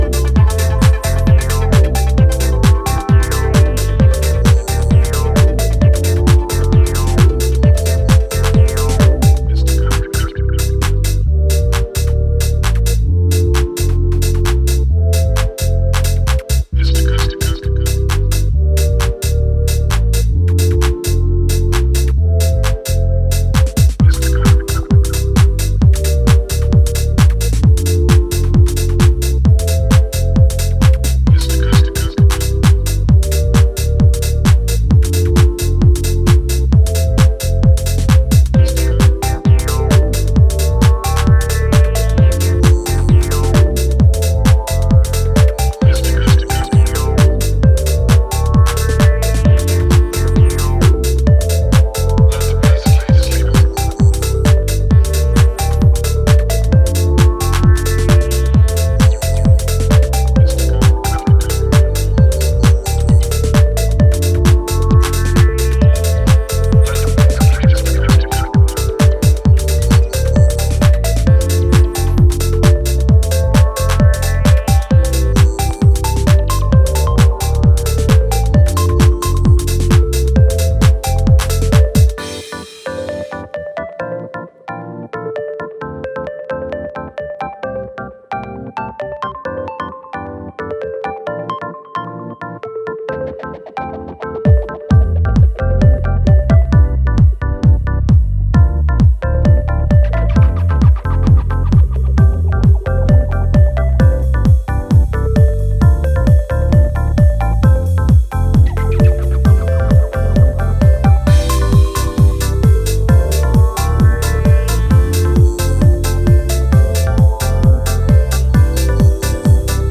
machine-driven dancefloor focused bombs